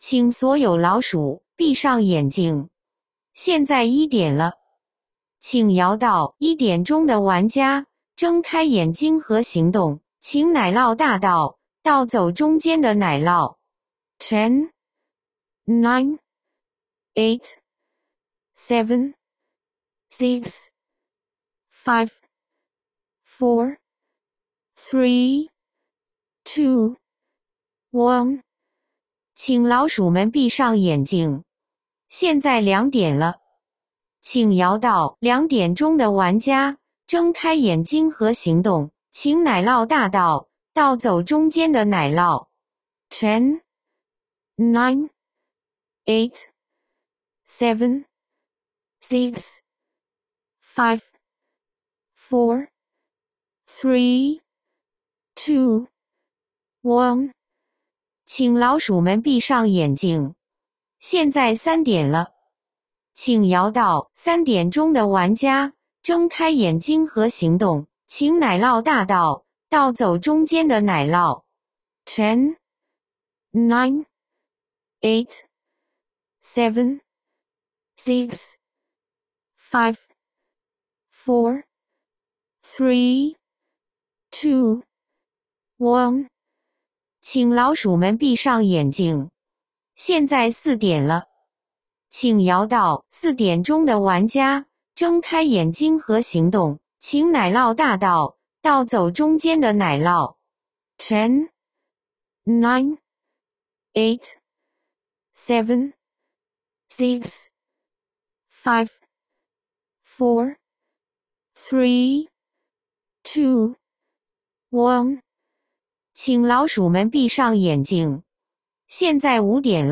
奶酪大盗桌游主持人配音，按 4–8 人局选择，可直接播放与下载。
cheese_thief_6p_host.m4a